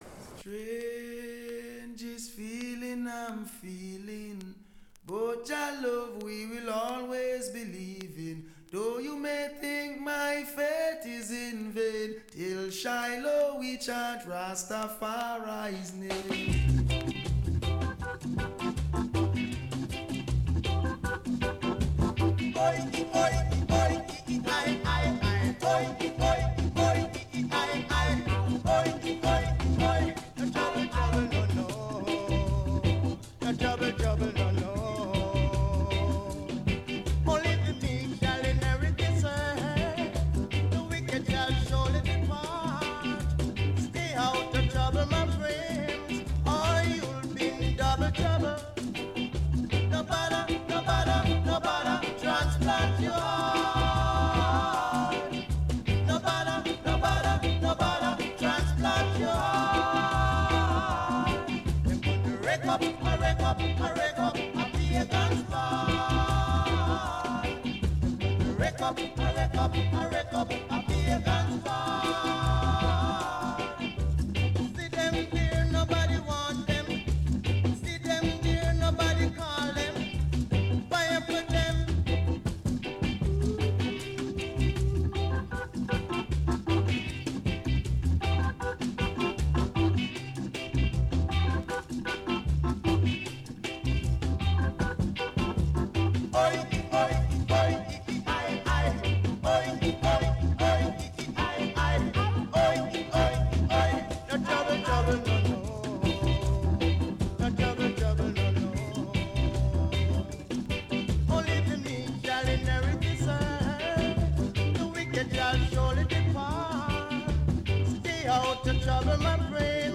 Sweet ska and rocksteady from the sixties, the deepest roots reggae from the seventies and the best dancehall from the eighties and beyond.